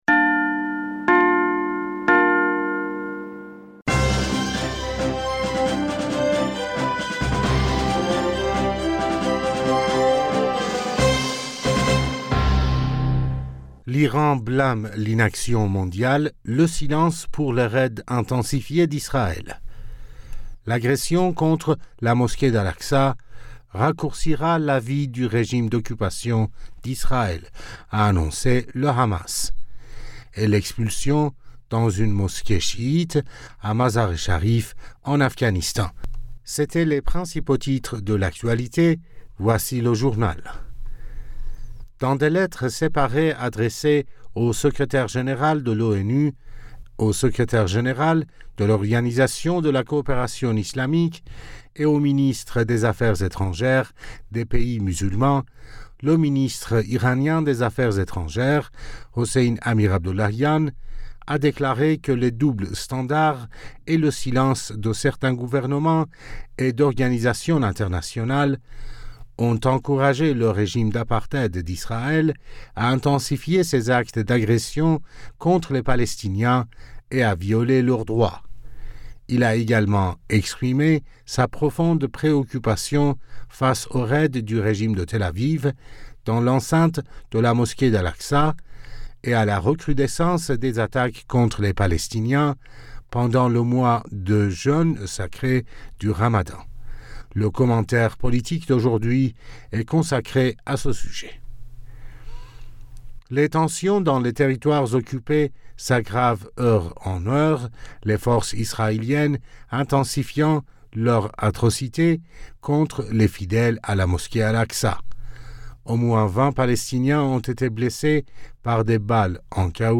Bulletin d'information Du 22 Avril 2022